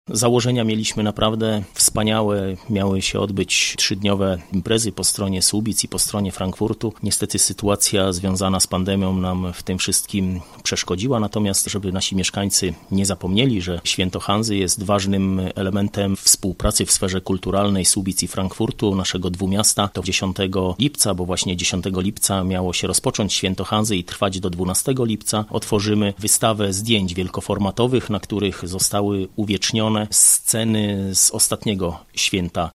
Ale nie możemy dać mieszkańcom zapomnieć, że to święto łączy nasze miasta – tłumaczy Mariusz Olejniczak, burmistrz Słubic.